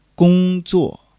gong1-zuo4.mp3